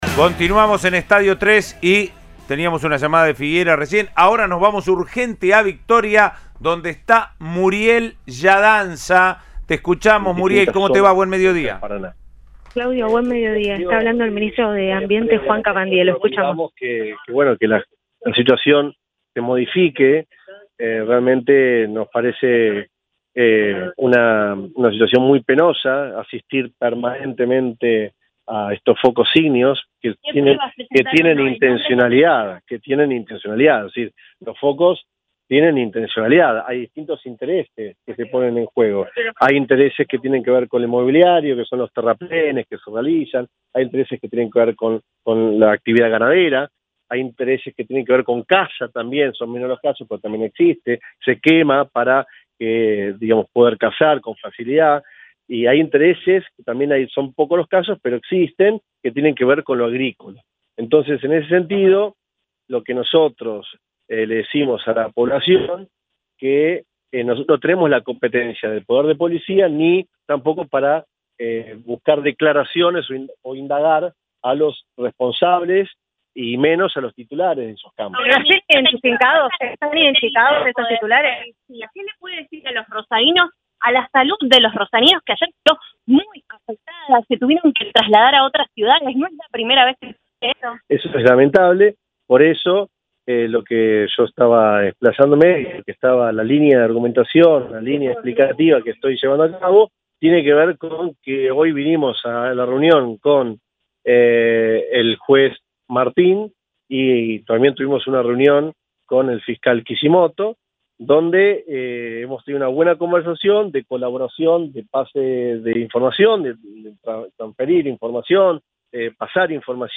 “Es una situación lamentable, no tenemos la competencia de la policía para poder sancionar y localizar a los culpables de las quemas”, dijo el ministro desde Victoria al móvil de Cadena 3 Rosario.